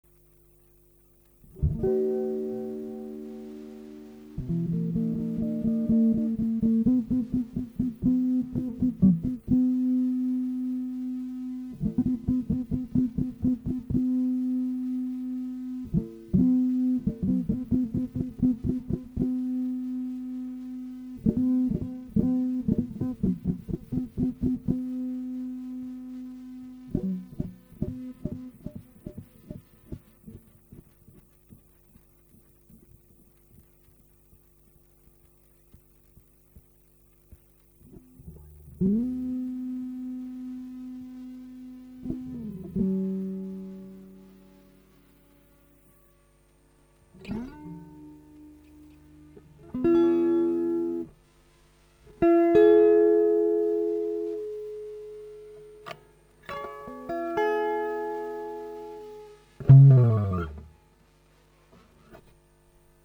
各種ノイズは多いし再生の音は